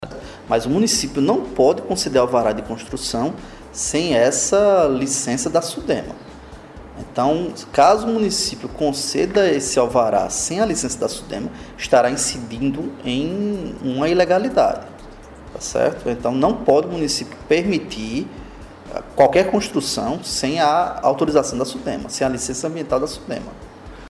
O promotor de justiça, Bruno Leonardo Lins em entrevista à Rádio 95FM, falou da situação envolvendo os loteamentos do município de Sumé. De acordo com ele, a Prefeitura de Sumé não poderá liberar alvarás de construção enquanto os loteamentos não forem regularizados.